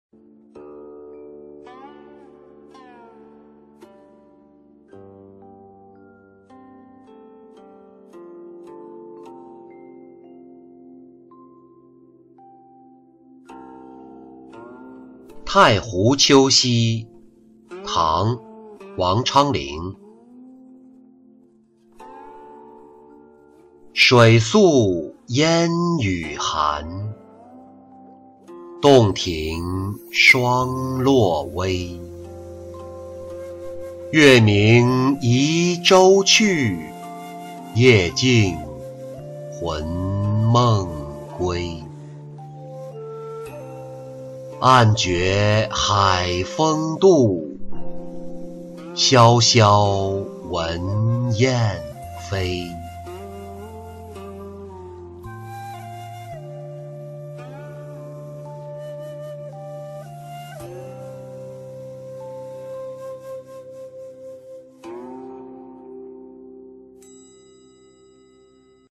太湖秋夕-音频朗读